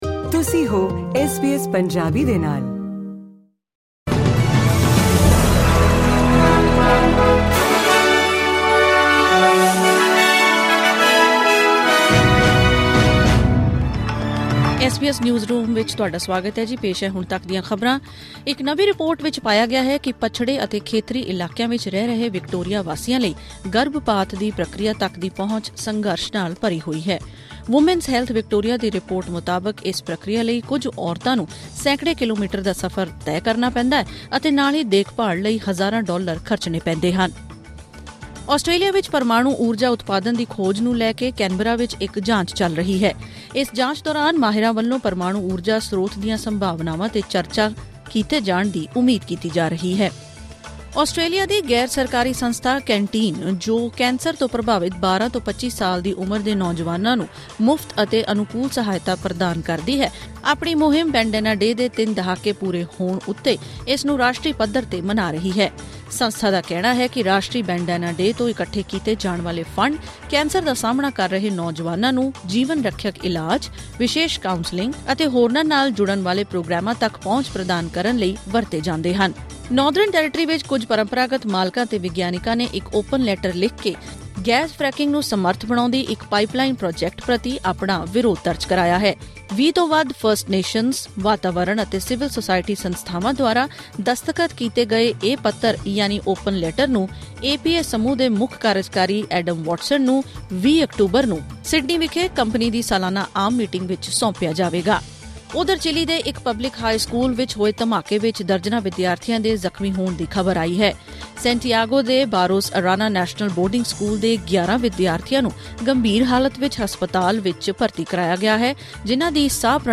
ਐਸ ਬੀ ਐਸ ਪੰਜਾਬੀ ਤੋਂ ਆਸਟ੍ਰੇਲੀਆ ਦੀਆਂ ਮੁੱਖ ਖ਼ਬਰਾਂ: 24 ਅਕਤੂਬਰ 2024